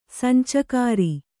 ♪ sancakāri